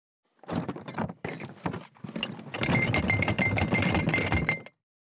地震,引っ越し,荷物運び,段ボール箱の中身,部署移動,ゴロゴロ,ガタガタ,,175
ゴロゴロ,ガタガタ,ドスン,バタン,ズシン,カラカラ,ギィ,ゴトン,キー,ザザッ,ドタドタ,バリバリ,カチャン,ギシギシ,ゴン,ドカン,ズルズル,タン,パタン,ドシン,地震,引っ越し,荷物運び,段ボール箱の中身,部署移動,
効果音